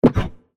かぷっと咥える 06
/ F｜演出・アニメ・心理 / F-18 ｜Move コミカルな動き
ブコッ